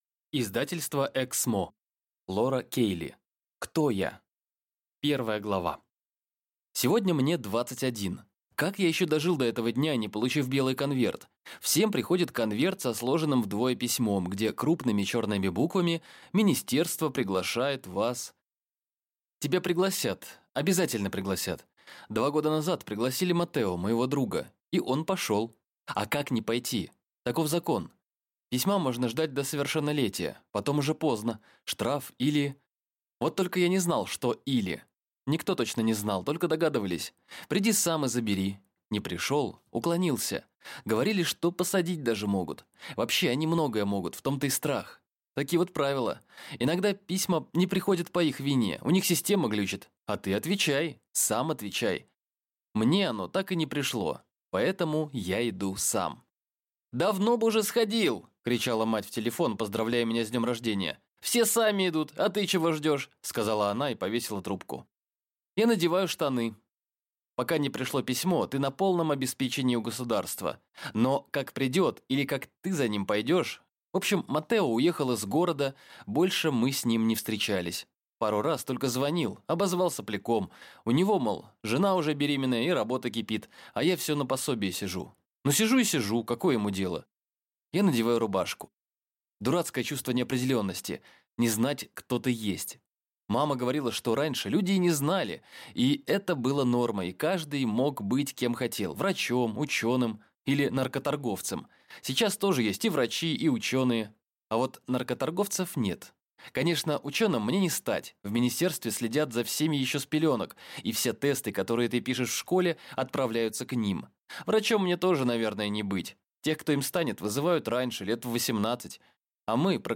Аудиокнига Кто я?